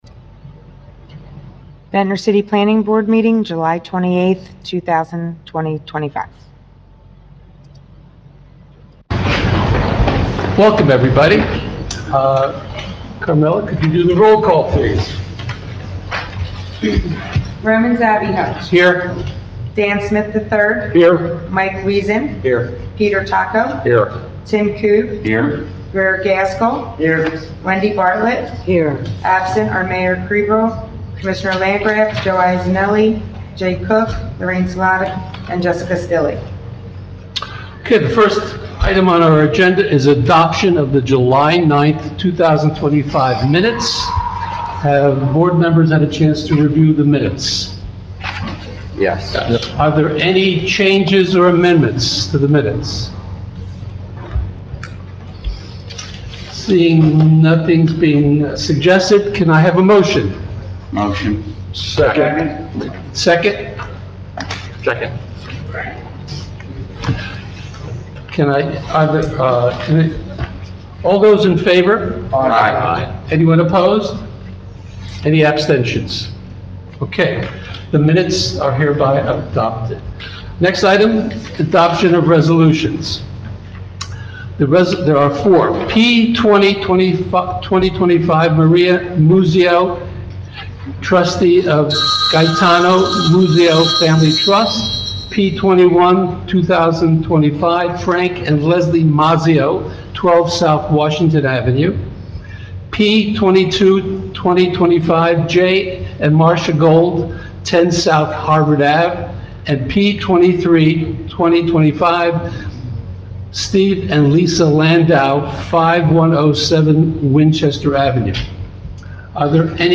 Ventnor City Planning Board Meeting